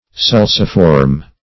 Sulciform \Sul"ci*form\, a. Having the form of a sulcus; as, sulciform markings.